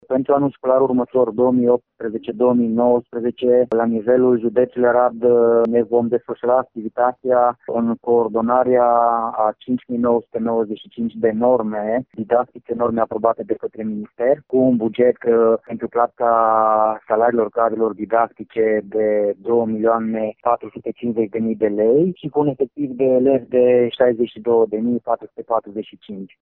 Pentru învăţământul profesional sunt 31 de clase cu 588 de elevi pentru învăţământul de trei ani, 17 clase cu 476 elevi pentru învăţământ tehnologic la seral şi 19 clase pentru postliceal, din care nouă cu taxă, spune șeful Inspectoratului Școlar Arad, Claudius Mladin.